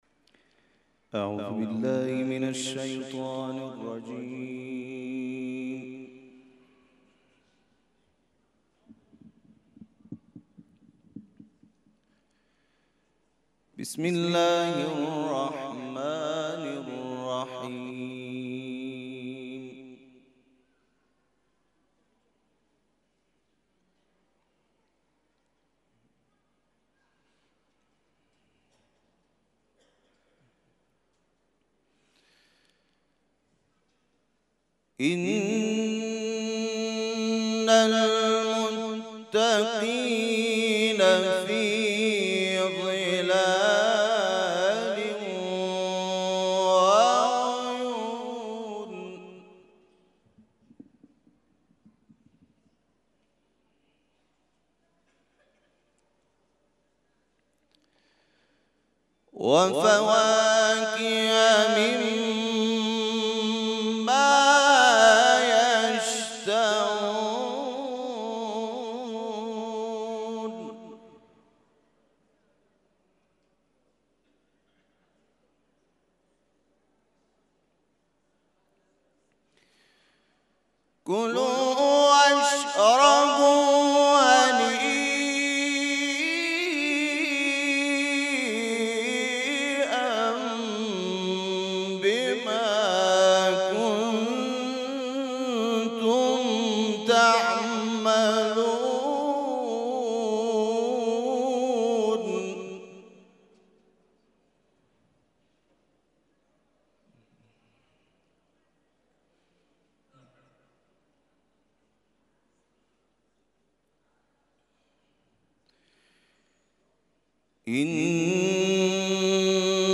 تلاوت مغرب
تلاوت قرآن کریم